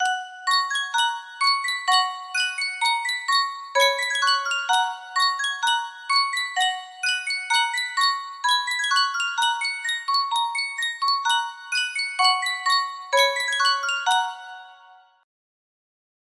Sankyo Music Box - Joseph Haydn Toy Symphony EG music box melody
Full range 60